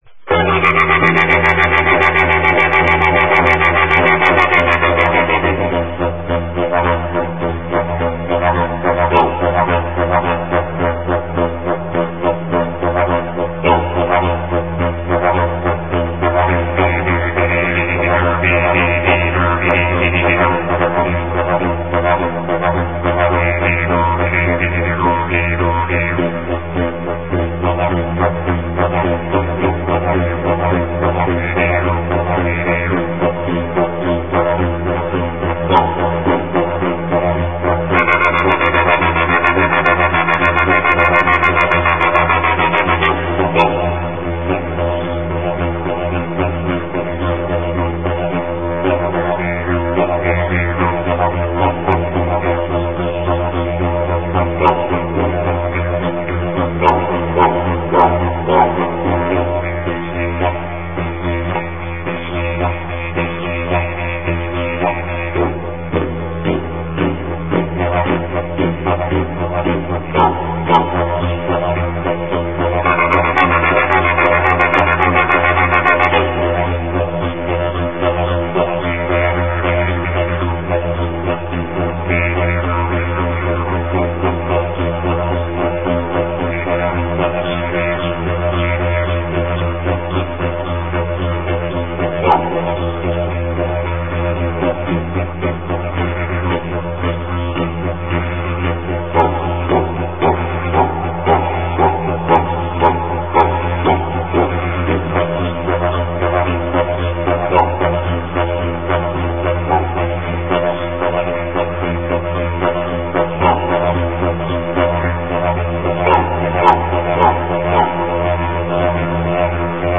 Traditional Didjeridu